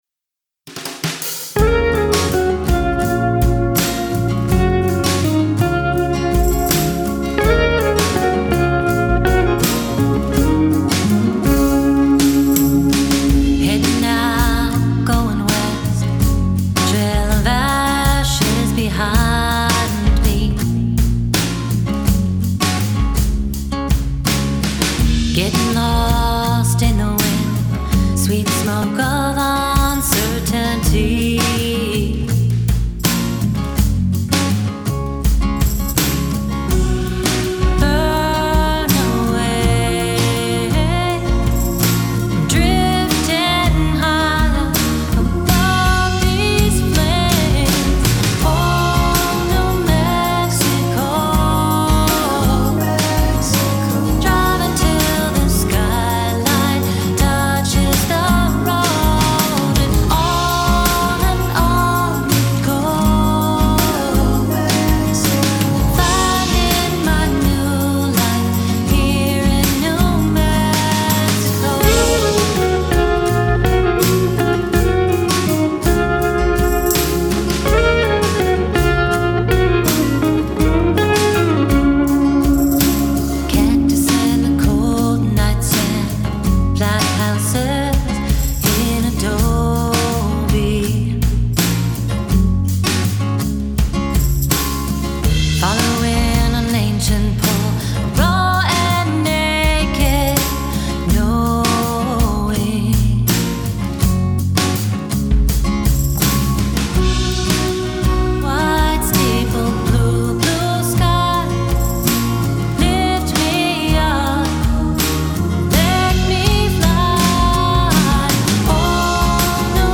Singer-songwriter